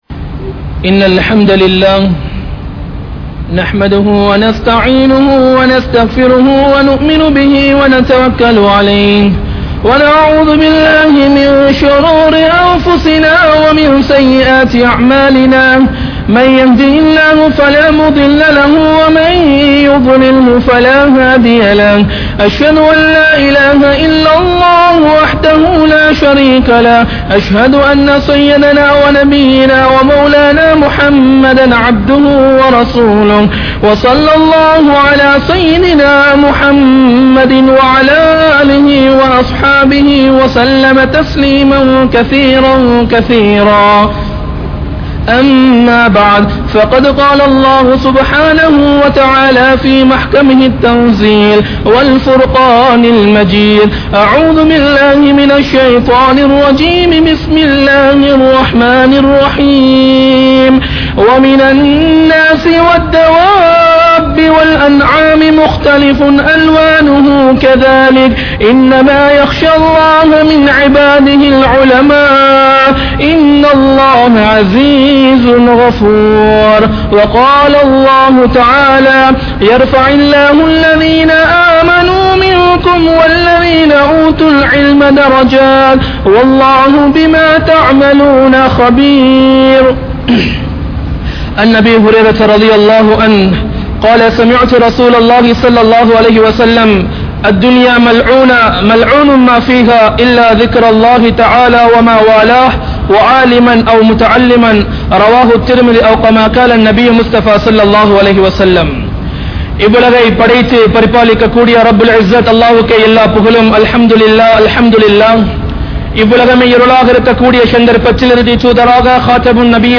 Ulamaakkalin Thiyaaham (உலமாக்களின் தியாகம்) | Audio Bayans | All Ceylon Muslim Youth Community | Addalaichenai
Kollupitty Jumua Masjith